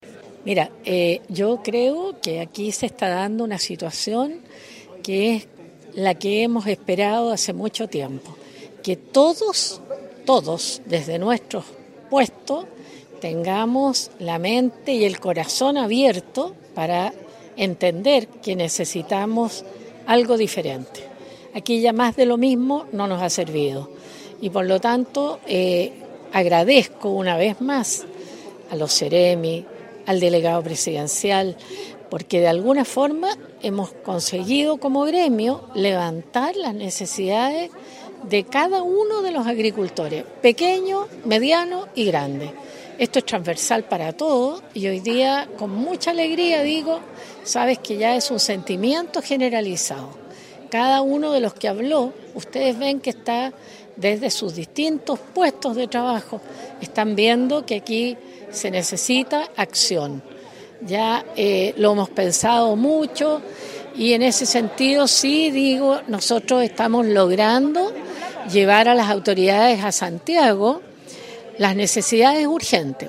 Fue el segundo encuentro regional de coordinación de estrategias para enfrentar la crisis hídrica reunió a parlamentarios, autoridades de Gobierno, consejeros regionales, dirigentes sociales, empresarios, agricultores y crianceros que plantearon sus problemáticas para ir levantando medidas para hacer frente a esta crisis.